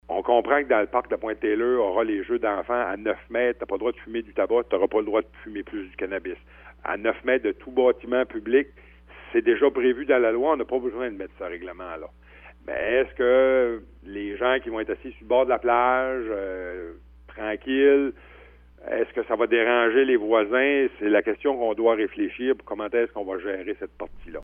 Le préfet de la MRC de Bonaventure, Éric Dubé, ne souhaite pas bannir la consommation partout dans l’espace public.  Sera-t-il possible de le permettre dans certains festivals, près de certains parcs de façon encadrée, le comité devra y réfléchir dit-il, quitte à resserrer le règlement au besoin :